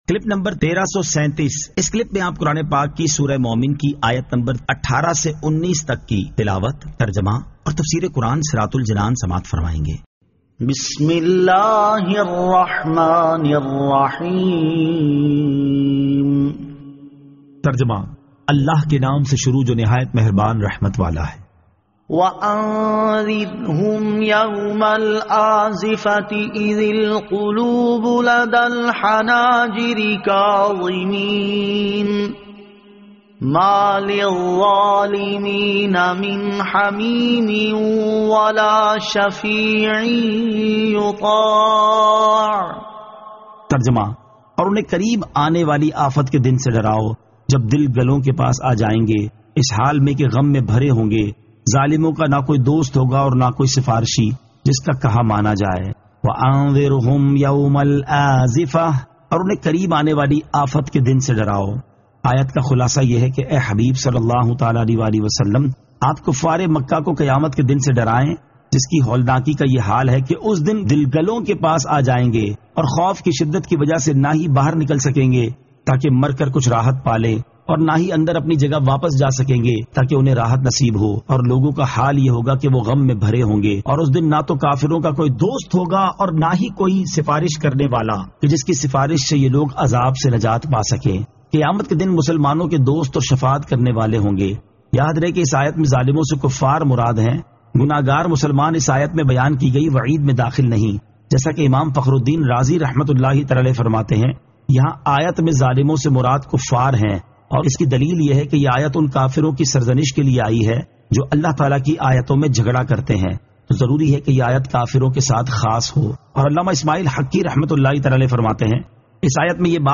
Surah Al-Mu'min 18 To 19 Tilawat , Tarjama , Tafseer